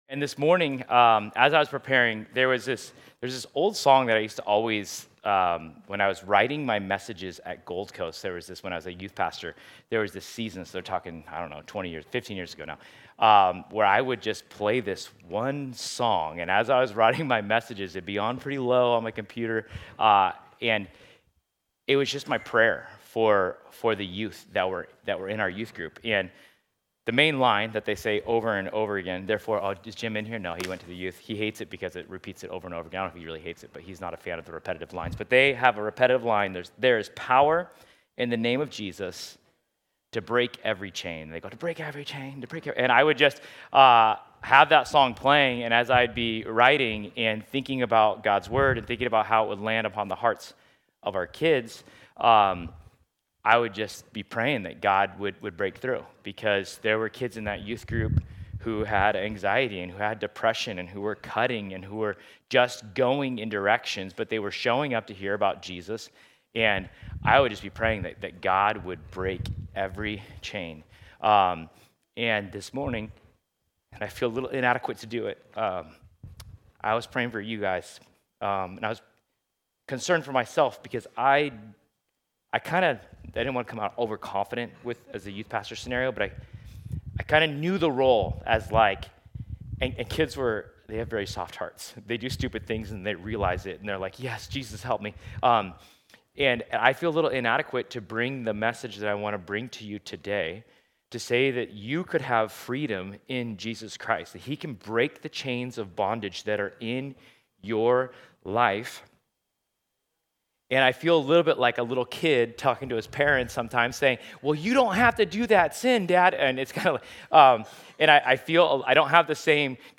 Galatians 5:16-26 Service Type: Sunday Set Free